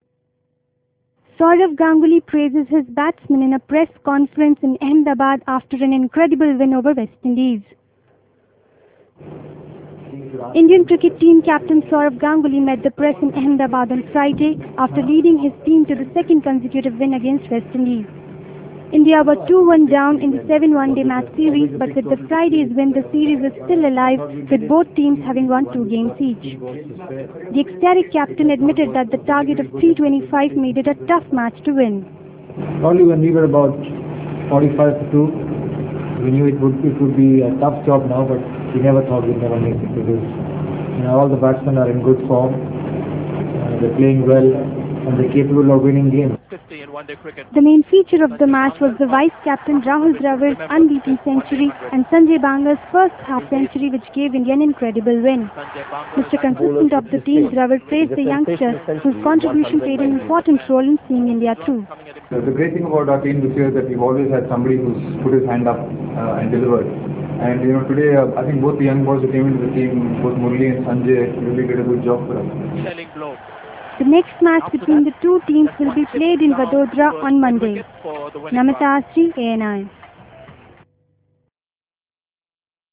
Saurav Ganguly praises his batsmen at a Press conference in Ahemdabad.